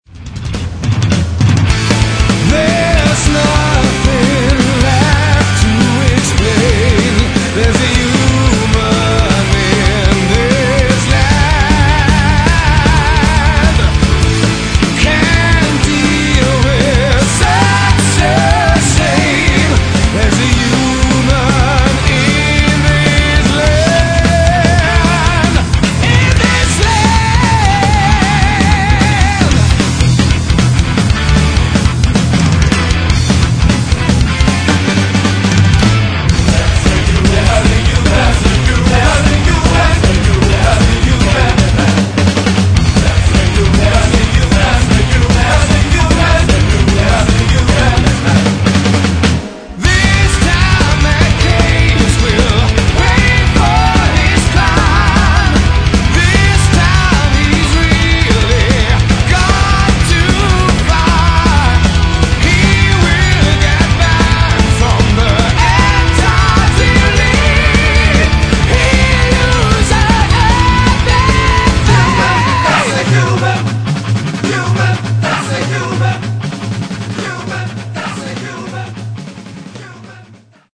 Drums & Percussions
(low quality)